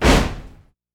stomp_03.wav